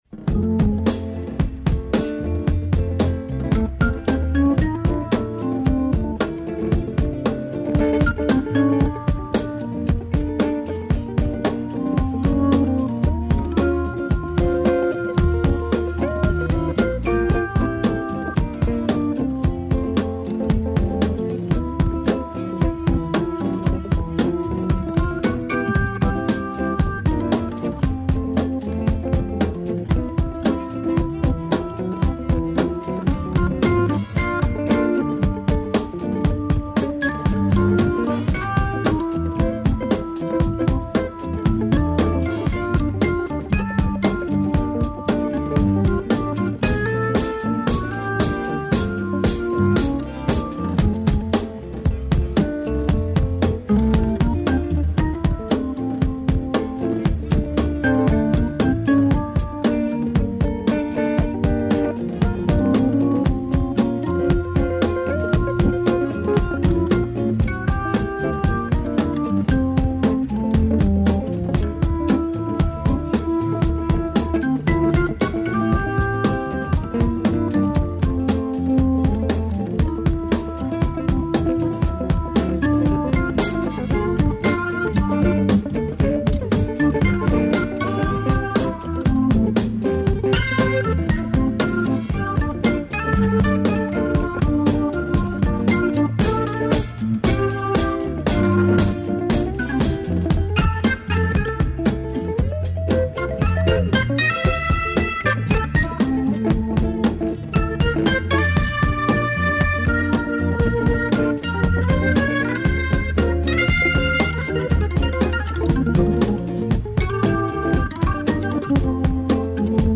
Албум садржи 11 нових песама и представља, по речима аутора, пунокрвну рокенрол плочу са великим бројем будућих хитова.